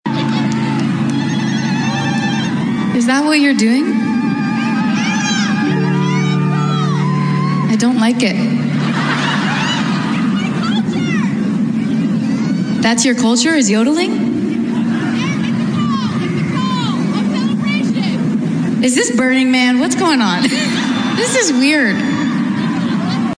Sabrina Carpenter is being criticized after mocking a fan who performed a ‘zaghrouta’, a mouth sound used to symbolize joy in Arab and African cultures, during a show at Coachella.
sabrina-carpenter-vs-crowd-member.mp3